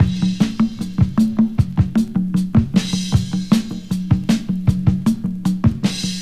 • 103 Bpm Drum Groove F# Key.wav
Free drum beat - kick tuned to the F# note. Loudest frequency: 453Hz
103-bpm-drum-groove-f-sharp-key-mxS.wav